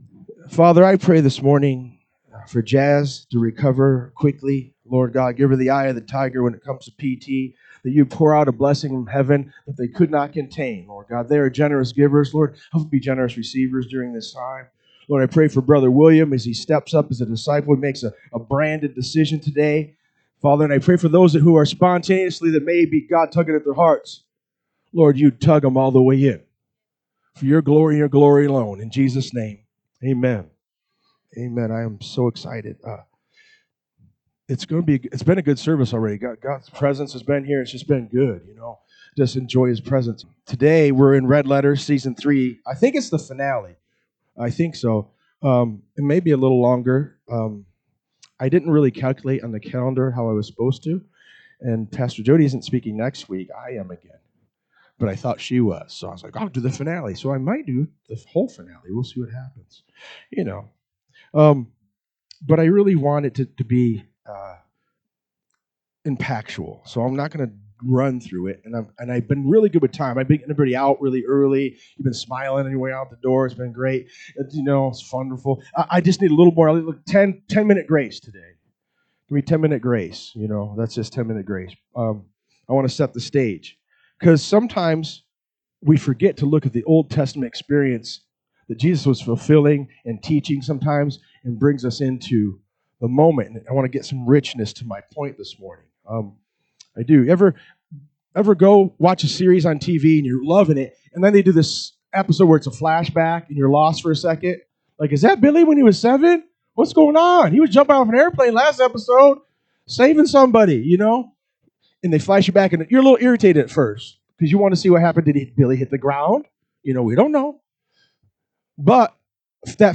NSCF Sermons Online Red Letters S3E4 - Listen for His Voice Jun 23 2025 | 00:42:39 1x 00:00 / 00:42:39 Subscribe Share RSS Feed Share Link Embed